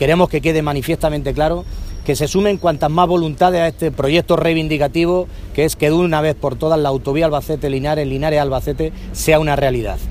Cortes de audio de la rueda de prensa
Audio Emilio Sáez